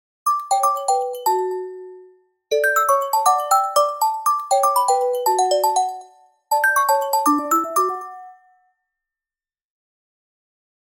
• Качество: 128, Stereo
Стандартный рингтон